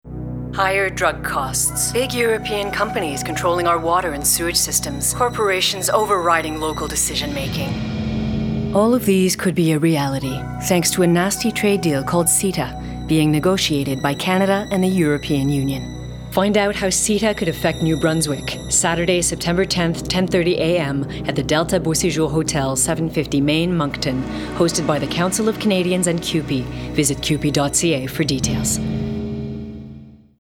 Attachments Event poster English Moncton Radio Ad English
CETA-MONCTON_30_Sec_ENGLISH_Radio.mp3